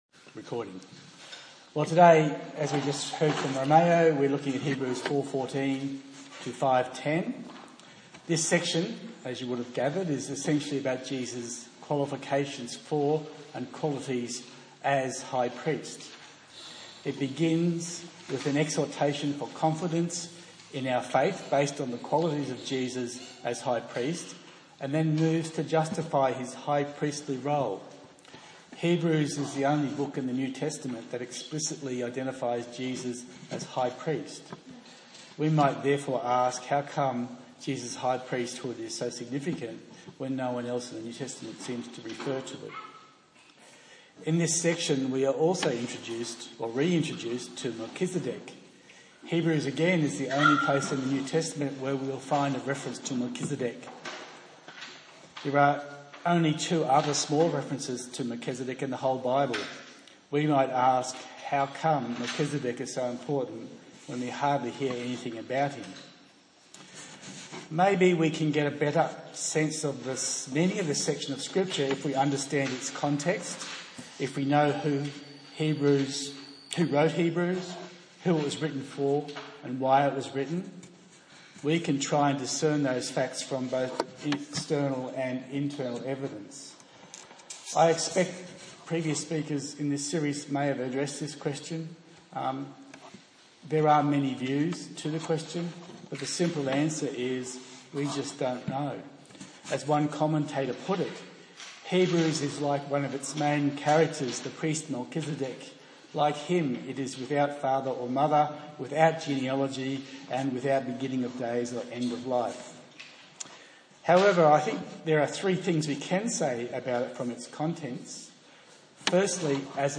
Passage: Hebrews 4:14-5:10 A sermon in the series on Hebrews